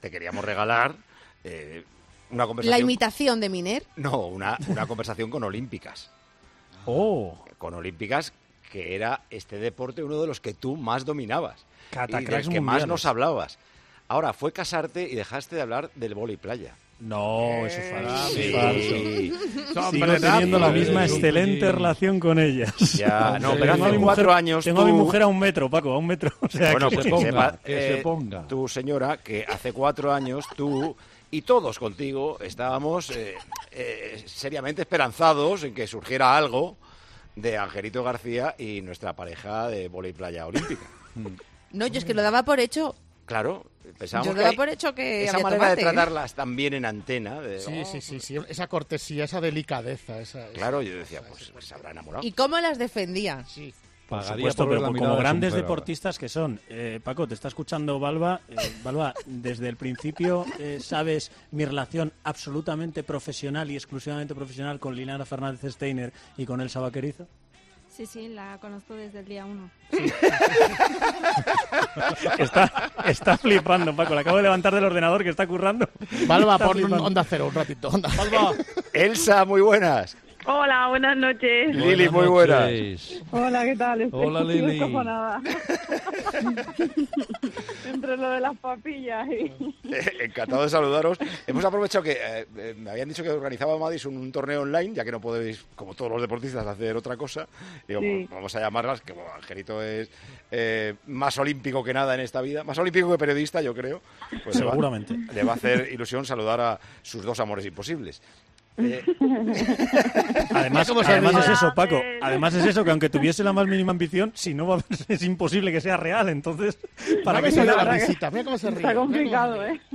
Entrevista a Elsa Baquerizo y Liliana Fernández, jugadoras de voley playa.